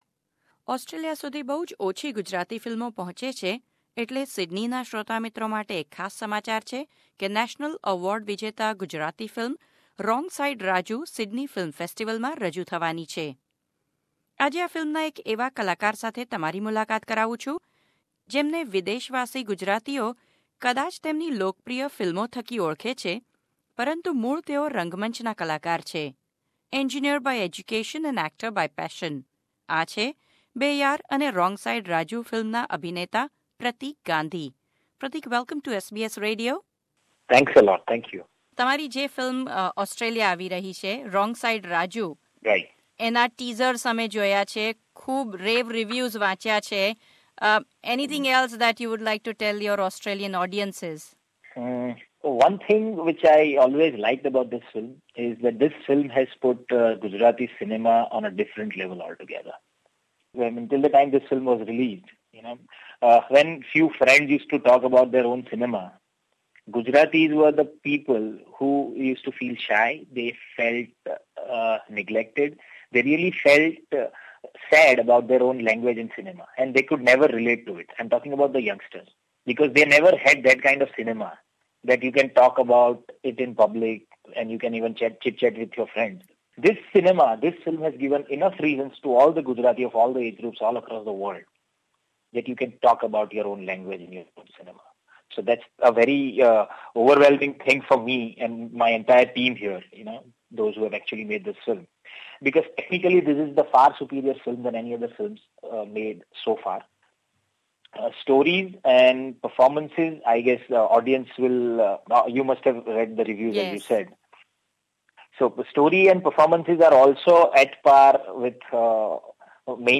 વાત-ચીત.